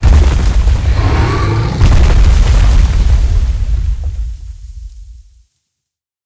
Sfx_creature_iceworm_jumpattack_short_01.ogg